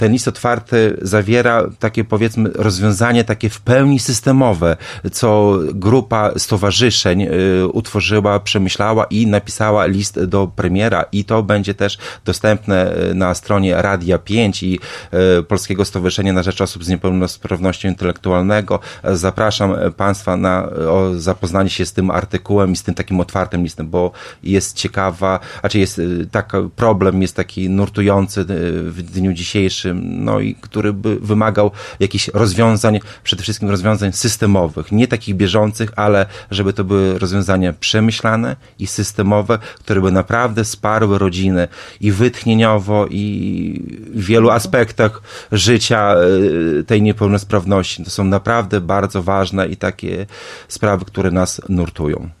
O szczegółach mówił w środę (23.05) w Radiu 5